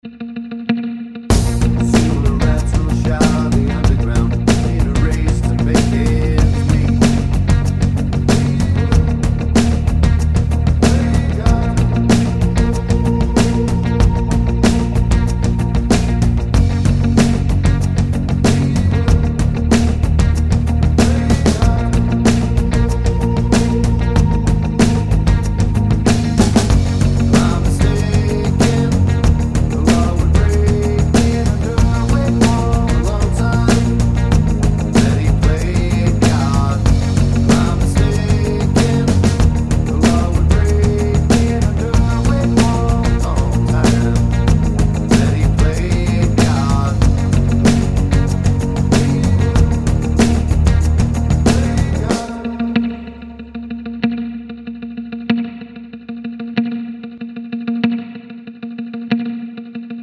no Backing Vocals Rock 3:45 Buy £1.50